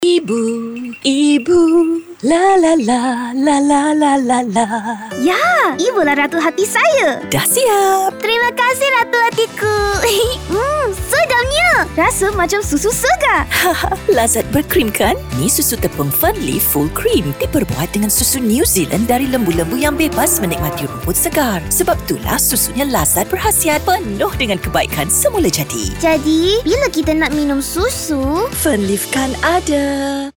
Female
Character Announcer Voice Prompt
Energetic Corporate Fatherly/Motherly Low Smooth Conversational
Showreel